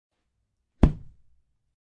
描述：打高尔夫球的高尔夫俱乐部的系列记录。用Zoom H1记录。
标签： 高尔夫 罢工 秋千 嗖嗖声 击打 系列 俱乐部 击中 蝙蝠 冲击
声道立体声